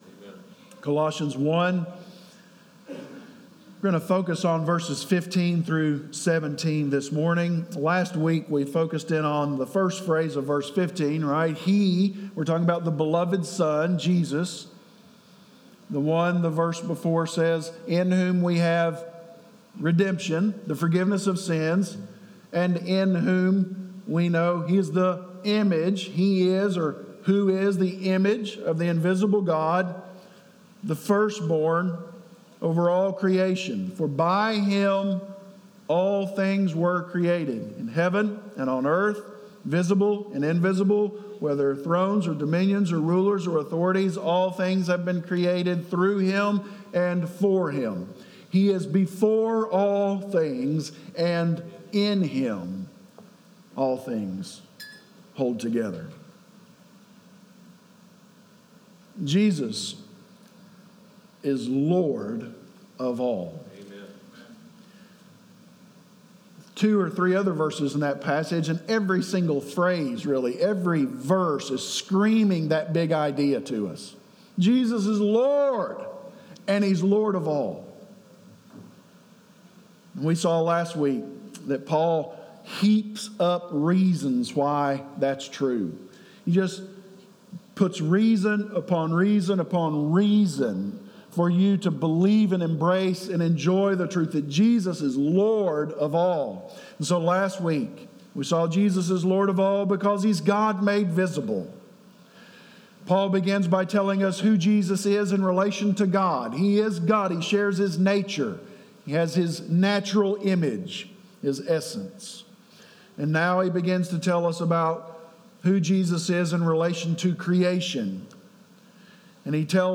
Sermonseries items dated: July, 2020